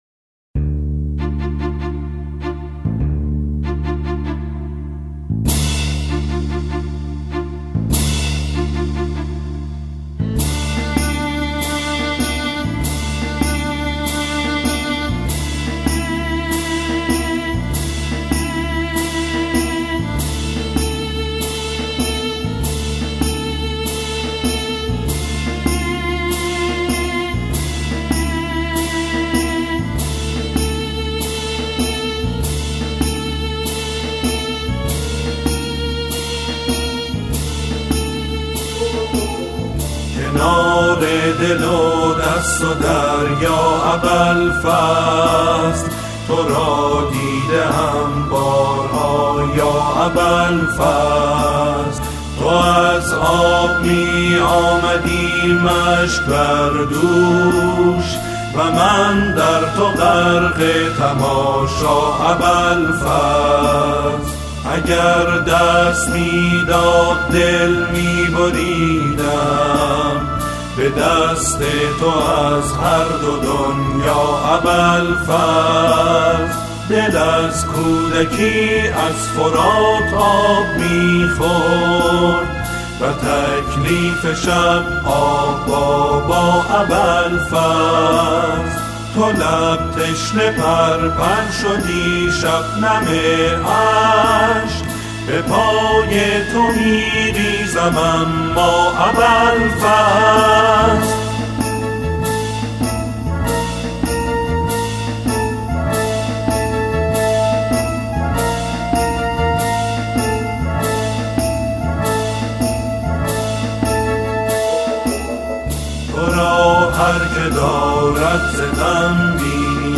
سرودهای حضرت عباس علیه السلام